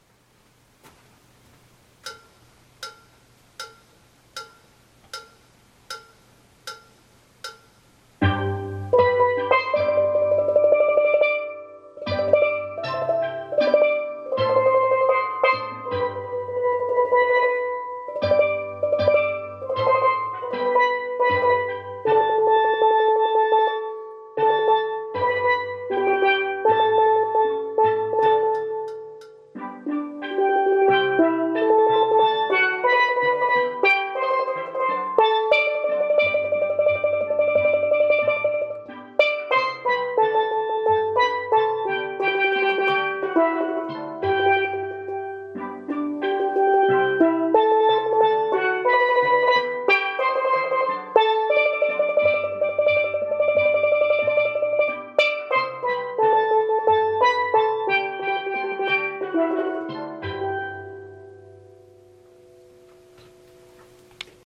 Ténor 1 Come Again Vid 78 90 .mp3